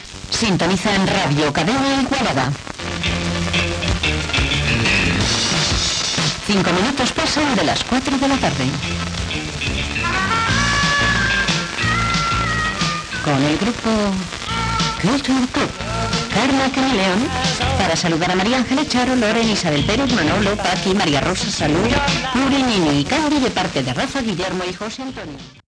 Identificació, hora i tema musical dedicat.
Musical
FM